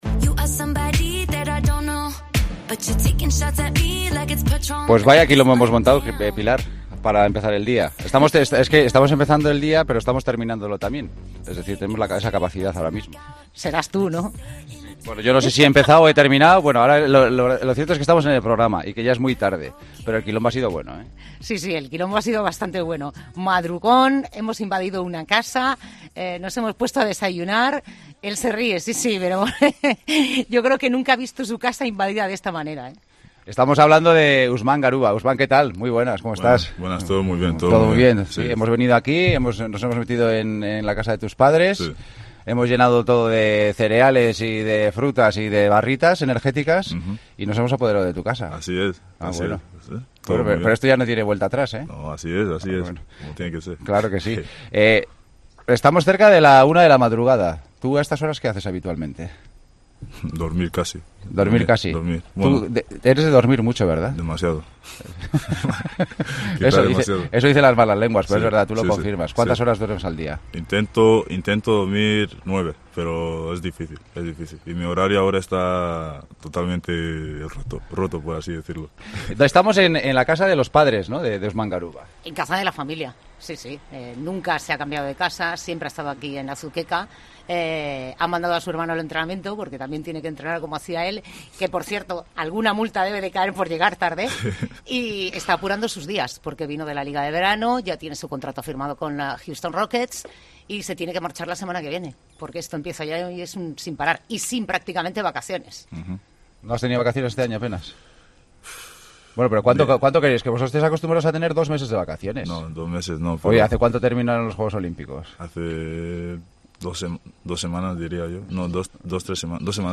Usman Garuba ha hablado con El Partidazo de COPE desde su casa de Azuqueca de Henares, a unos días de marcharse definitivamente a Houston a vivir su sueño de jugar en la NBA y de enfrentar a jugadores como, "Antetokoumpo", en palabras del propio Usman, el rival con el que más ganas tiene de compartir pista.
Entrevista